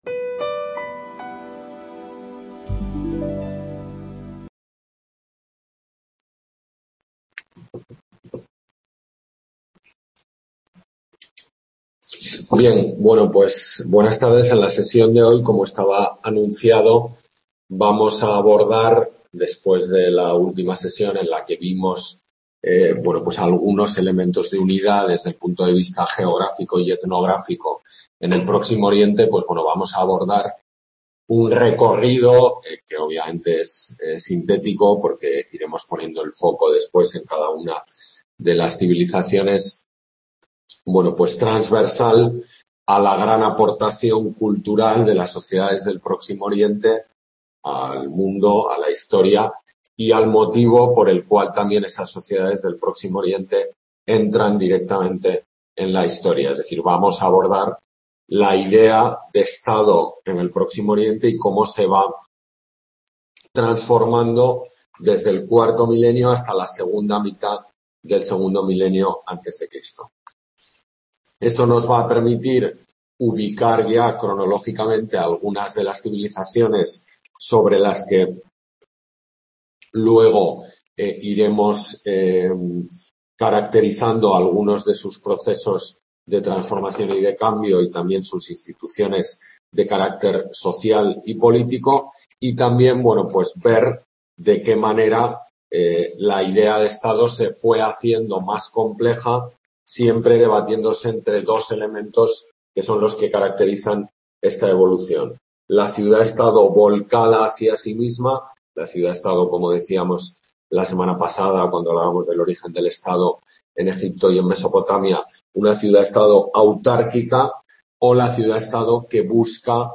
Tutoría de Historia Antigua I en la UNED de Tudela